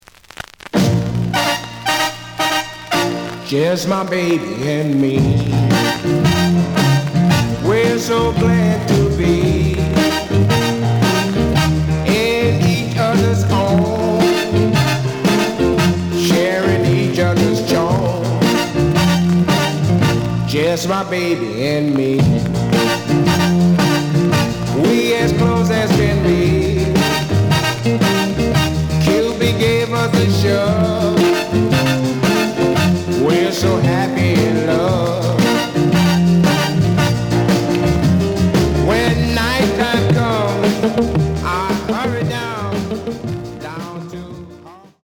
試聴は実際のレコードから録音しています。
●Genre: Rhythm And Blues / Rock 'n' Roll
●Record Grading: G+ (両面のラベルにダメージ。盤に若干の歪み。傷は多いが、A面のプレイはまずまず。)